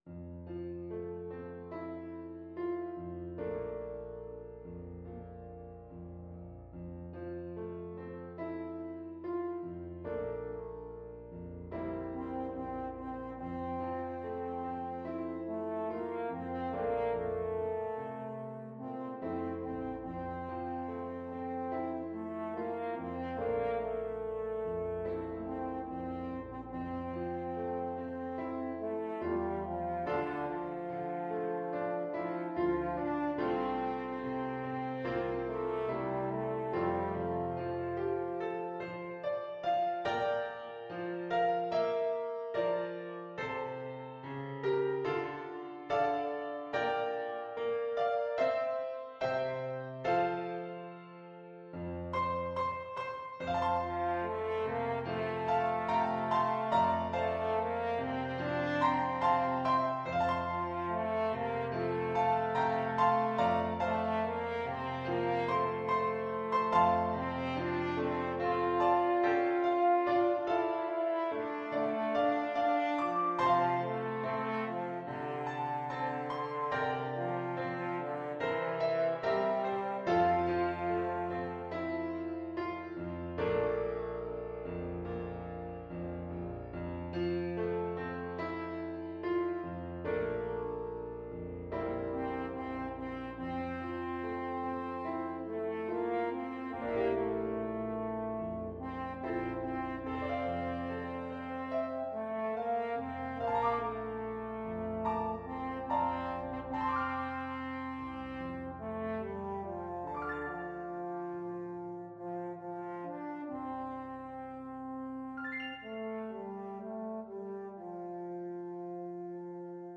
French Horn
Traditional Music of unknown author.
4/4 (View more 4/4 Music)
D4-F5
F major (Sounding Pitch) C major (French Horn in F) (View more F major Music for French Horn )
Molto espressivo =c.72
Welsh